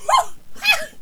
princess_attack2.wav